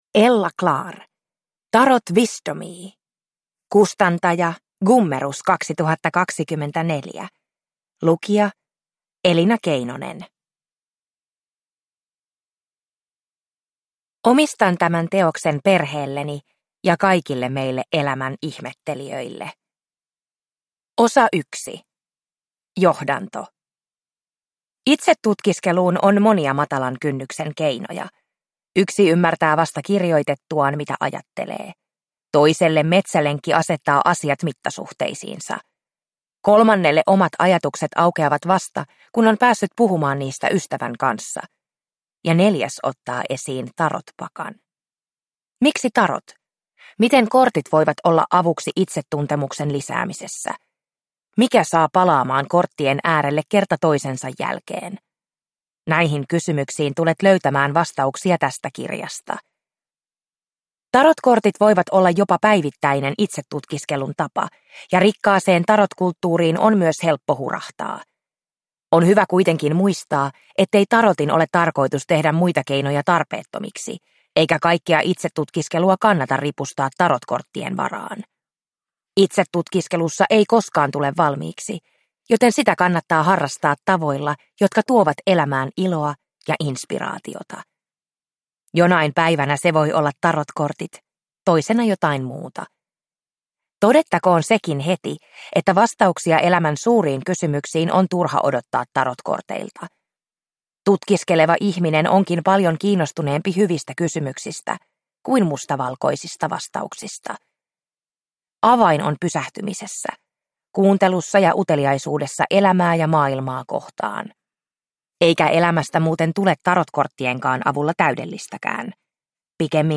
Tarotwisdomii – Ljudbok